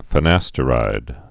(fə-năstə-rīd)